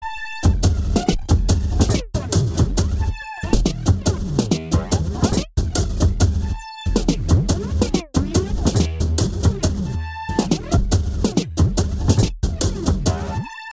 尖叫的鼓声三
描述：法兰式环路3
Tag: 140 bpm Breakbeat Loops Drum Loops 2.31 MB wav Key : Unknown